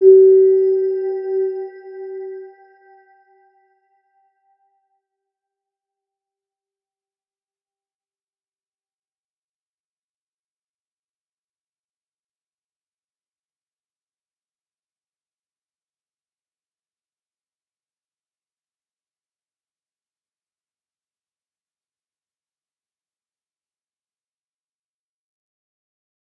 Round-Bell-G4-mf.wav